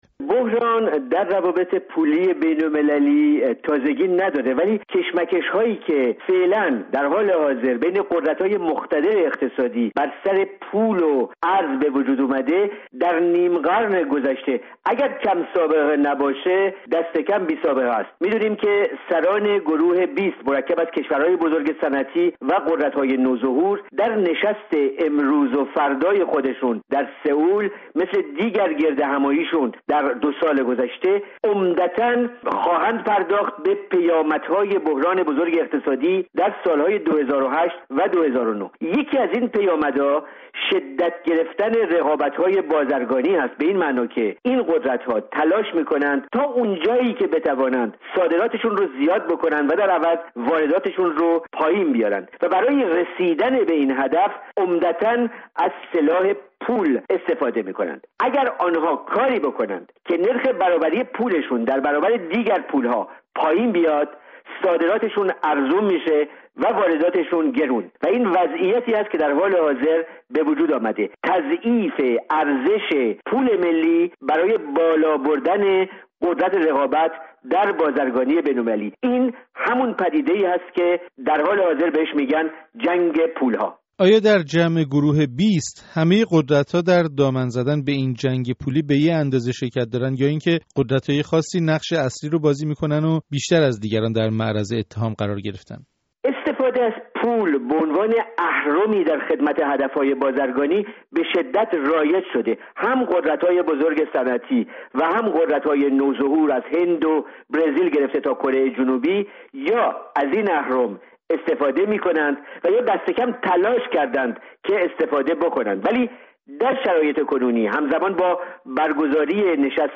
گفتار اقتصادی